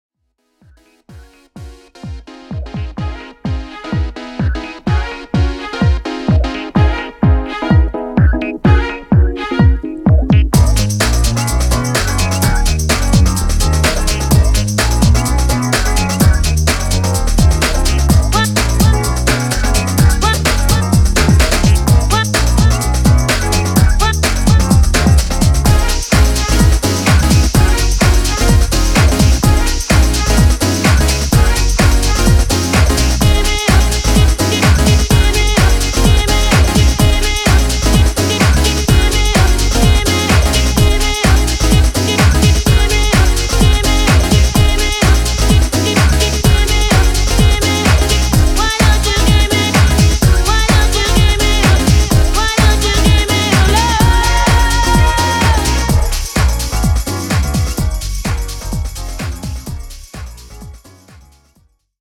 アップリフトな